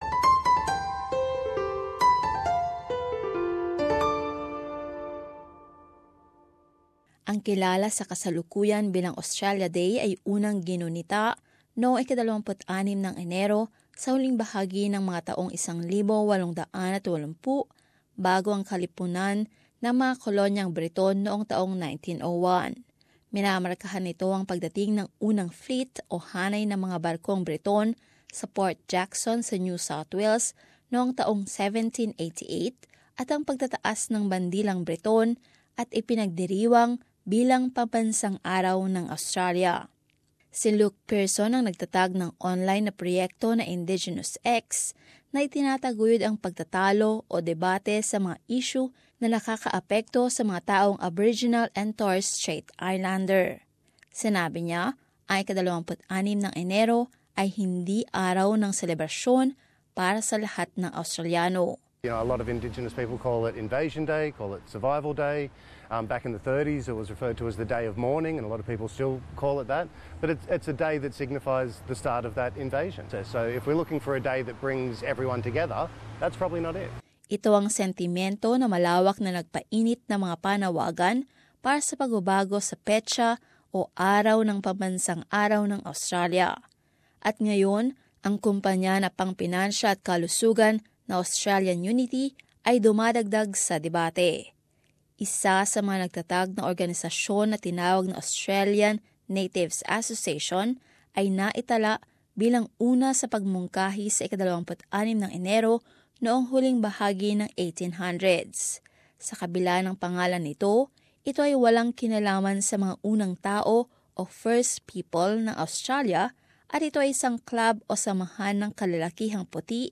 In this report, its adding to calls for a day that would be seen as more inclusive in its meaning of Australia's Indigenous people.